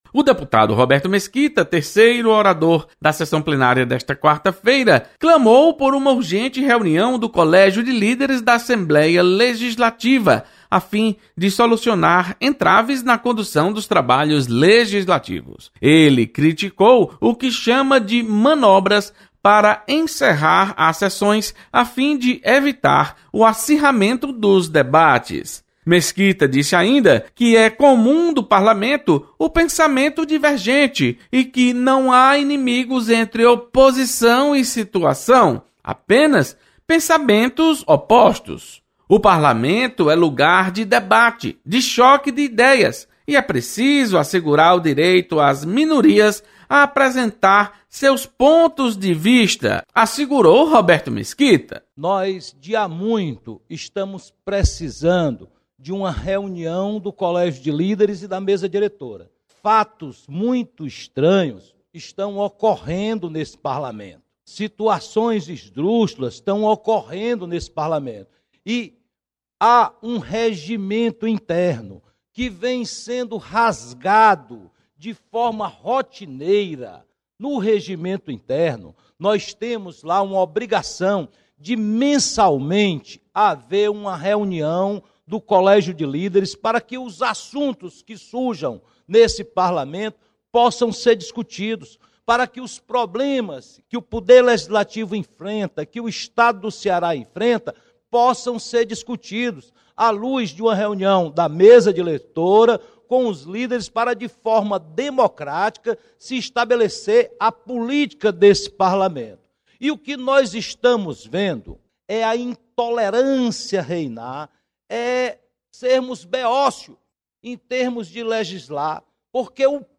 Deputado Roberto Mesquita solicita reunião do Colégio de Líderes da Assembleia. Repórter